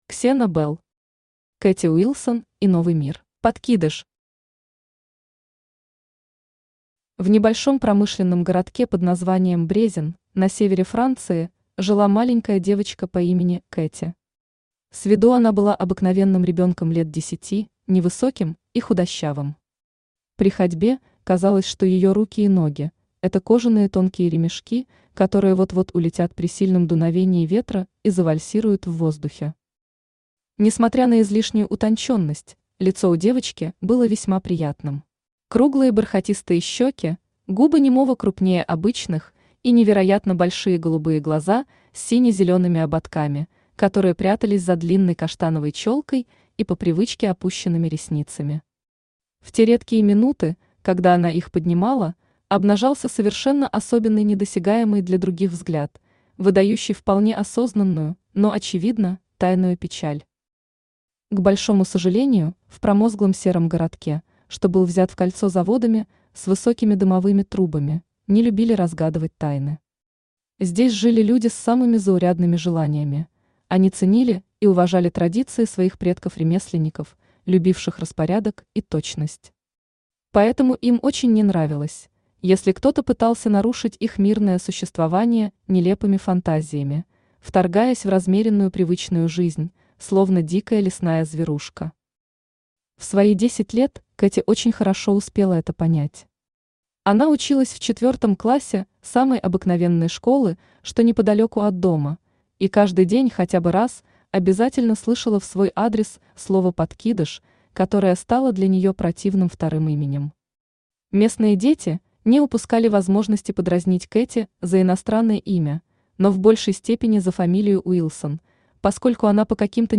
Аудиокнига Кэти Уилсон и Новый мир | Библиотека аудиокниг
Aудиокнига Кэти Уилсон и Новый мир Автор Ксена Белл Читает аудиокнигу Авточтец ЛитРес.